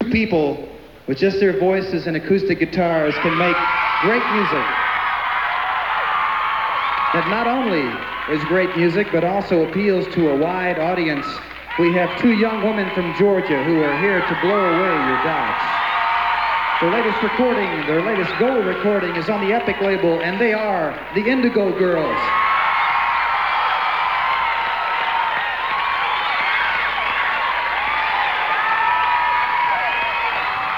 lifeblood: bootlegs: 1990: 1990-08-11: newport folk festival - newport, rhode island (alternate)
(acoustic duo show)
01. introduction (0:30)
(radio broadcast)